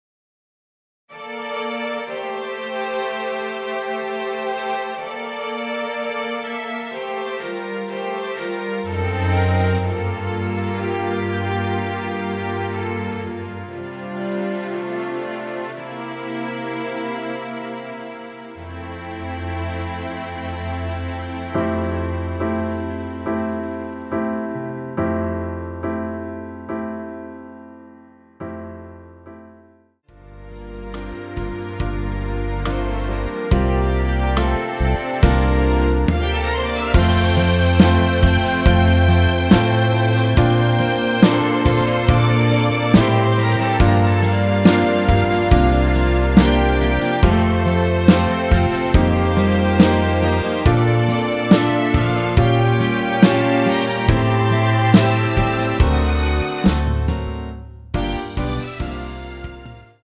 원키에서(+2)올린 MR입니다.
앞부분30초, 뒷부분30초씩 편집해서 올려 드리고 있습니다.
중간에 음이 끈어지고 다시 나오는 이유는